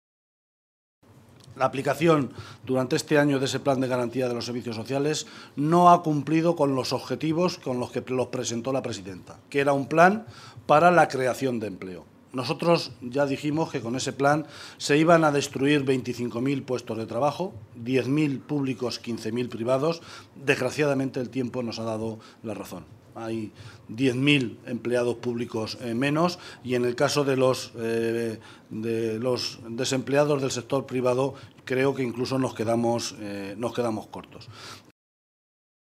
José Luis Martínez Guijarro, portavoz del Grupo Parlamentario Socialista
Cortes de audio de la rueda de prensa